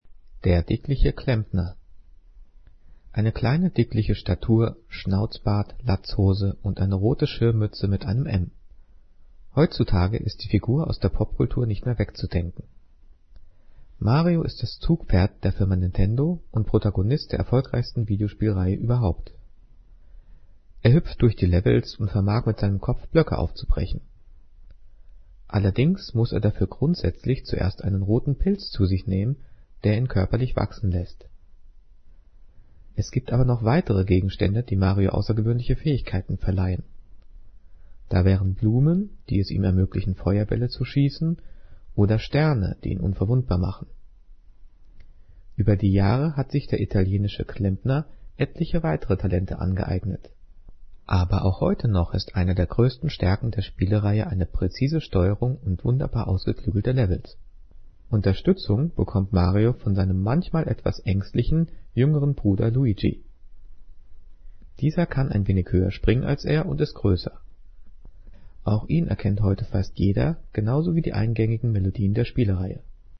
Gelesen:
gelesen-der-dickliche-klempner.mp3